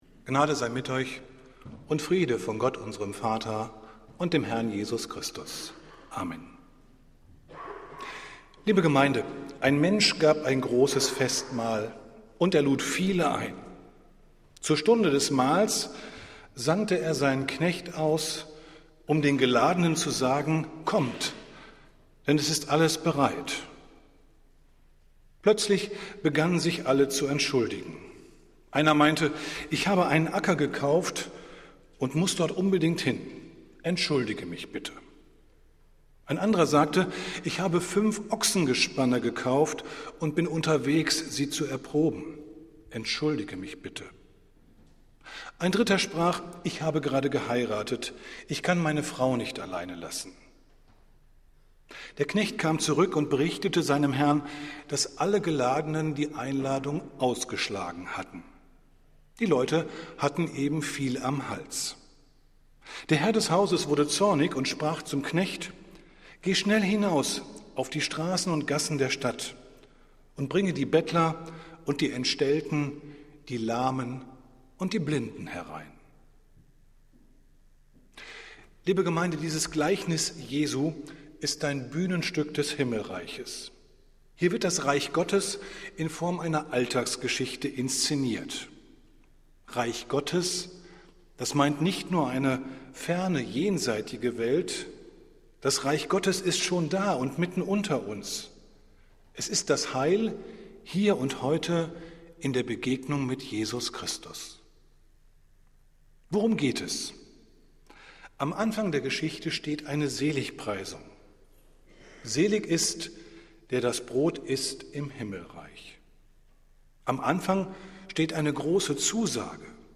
Predigt des Gottesdienstes aus der Zionskirche am Sonntag, den 18. Juni 2023
Wir haben uns daher in Absprache mit der Zionskirche entschlossen, die Predigten zum Nachhören anzubieten.